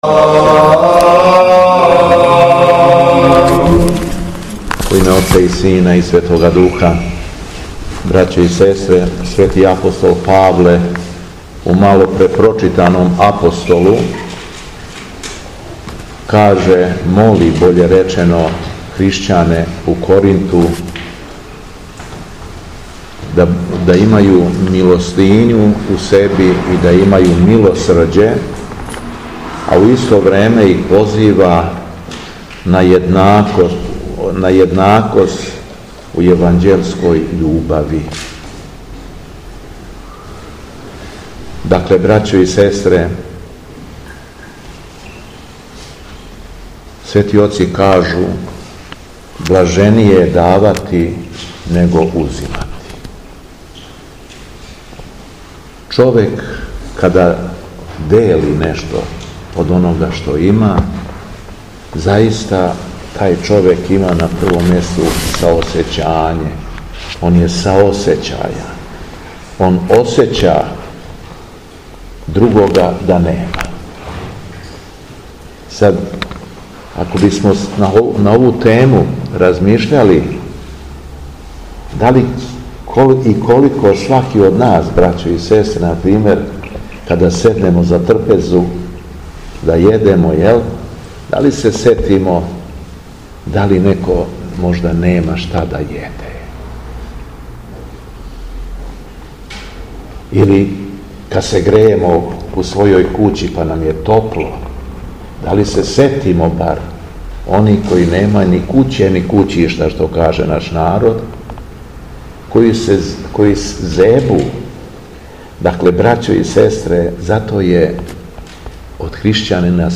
Беседа Његовог Високопреосвештенства Митрополита шумадијског г. Јована
После прочитаног Јеванђеља Високопреосвећени владика се обратио верном народу беседом рекавши: